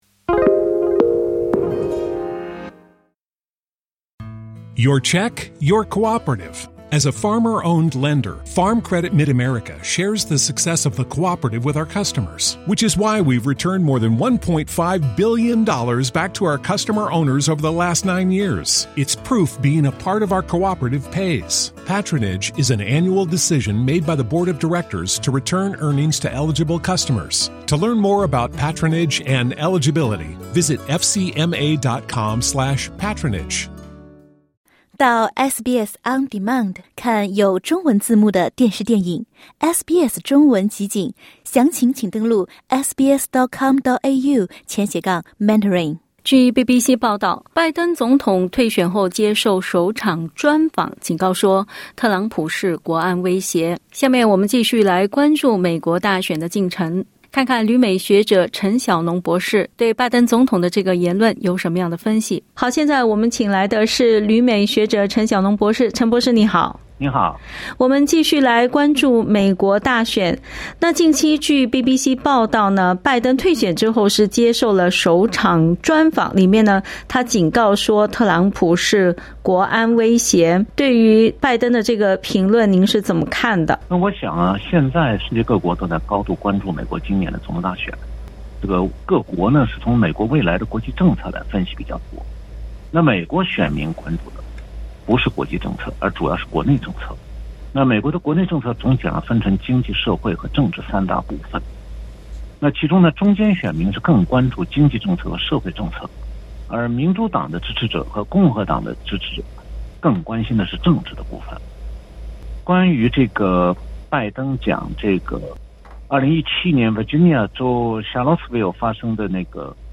（点击音频收听详细采访） 据报道，特朗普总统（Donald Trump）本周将对英国进行国事访问，他将要求欧盟国家停止从俄罗斯进口石油，并对中国和印度加征百分之五十到一百的关税。